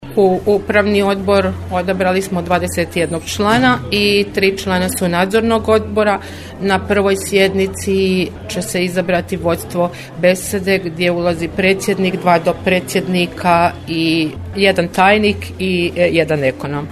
Održana izborna skupština Češke besede Daruvar